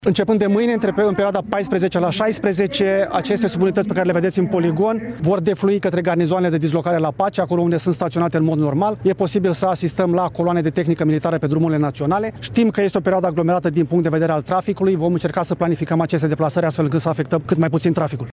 Reprezentant al MApN: „E posibl să asistăm la coloane de tehnică militară pe drumurile naționale”